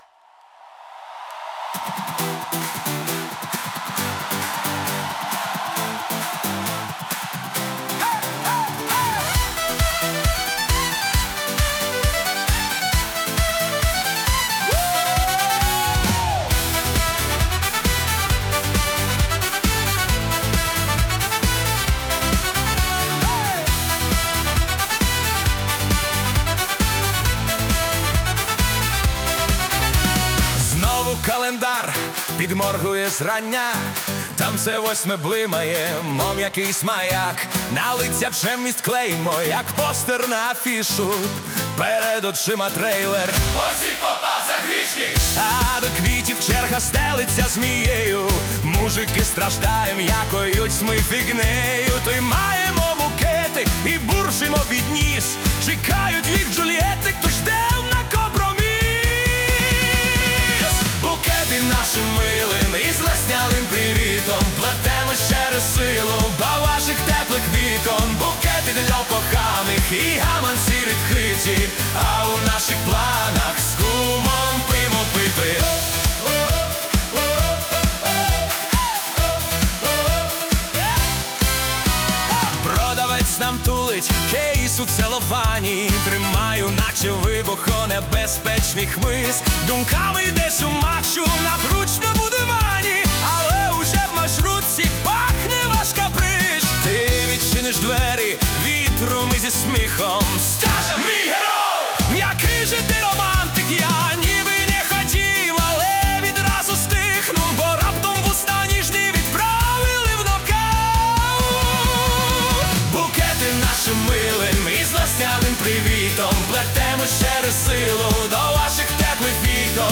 Стиль: Поп